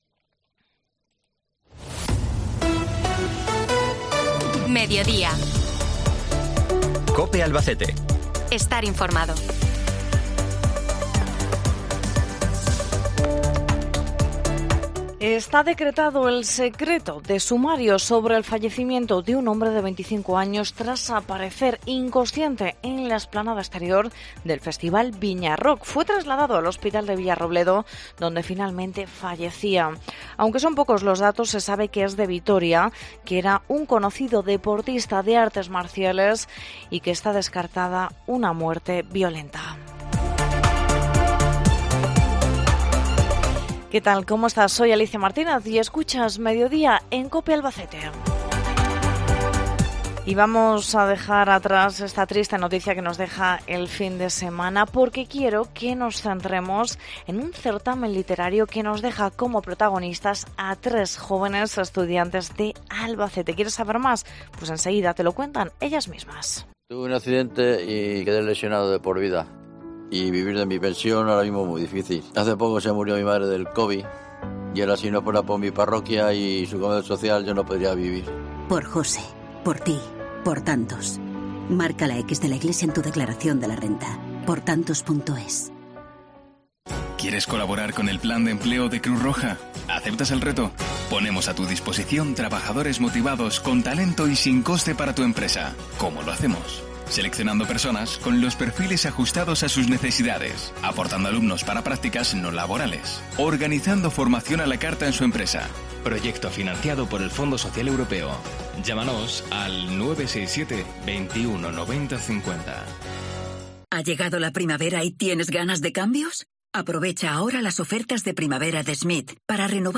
Hoy hablamos con las 3 finalista del I certamen literario de la Asociación Alborea